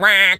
Animal_Impersonations
duck_quack_hurt_05.wav